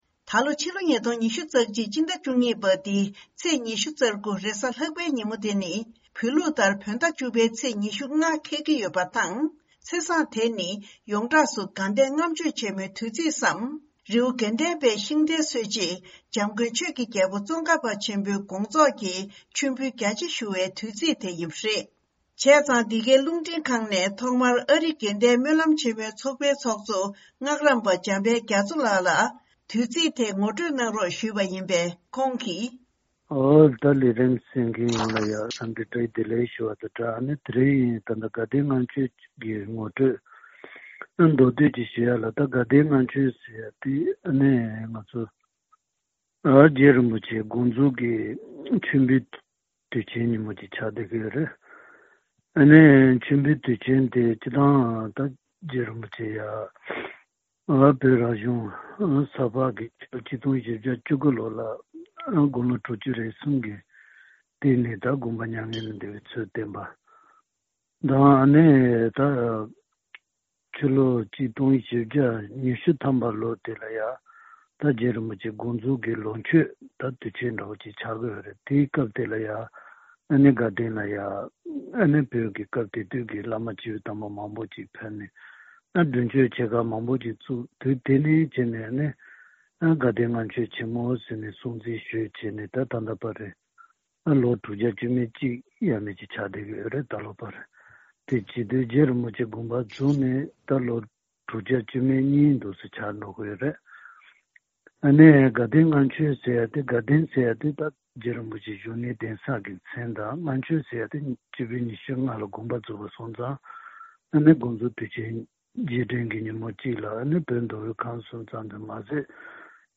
འབྲེལ་ཡོད་མི་སྣར་བཅར་འདྲི་ཞུས་ཏེ་གནས་ཚུལ་ཕྱོགས་བསྒྲིགས་གནང་པ་དེ་གསན་རོགས་གནང་།